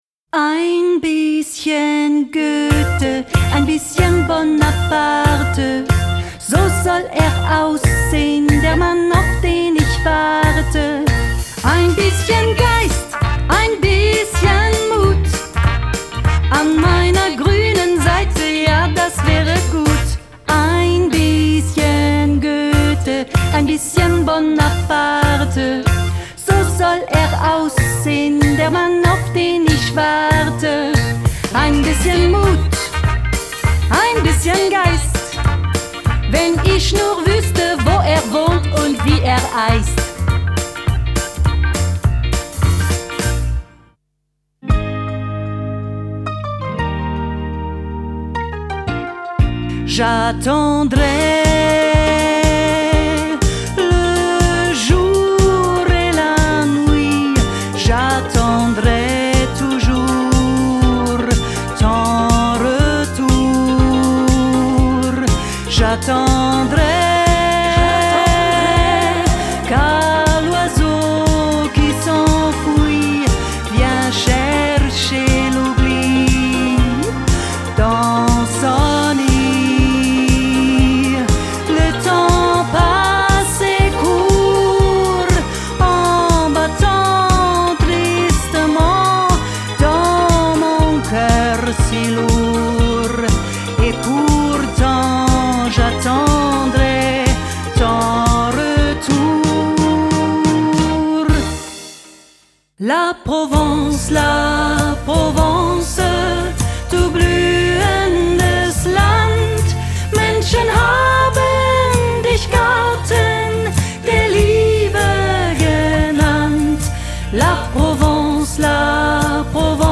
Schlager à la française